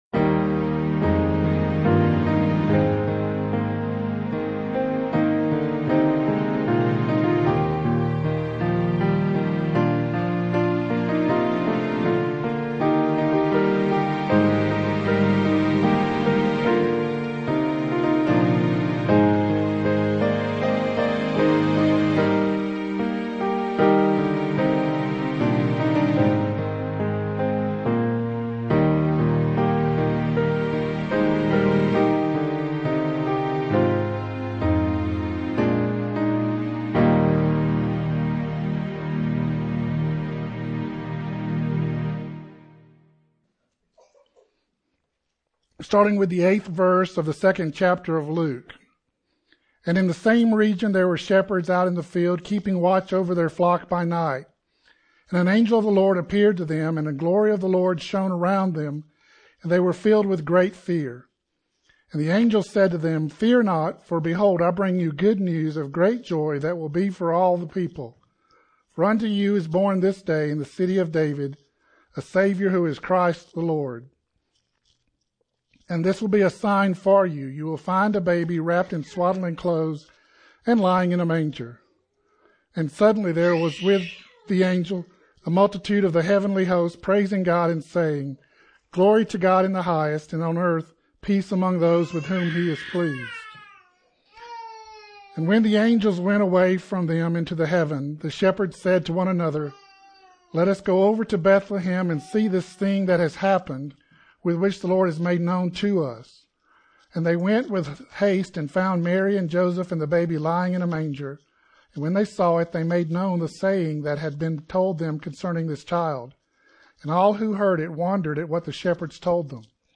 The latest sermon & selected archives from Castle Rock Baptist Church, Castle Rock, Colorado.
2024ChristmasEveMessage.mp3